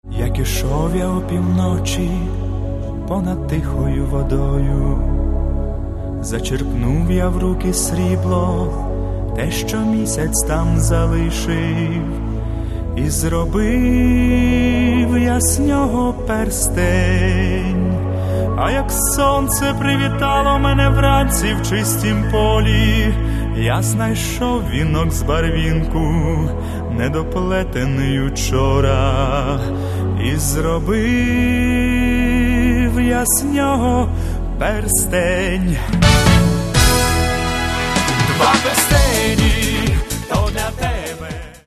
в исполнении современных поп-артистов.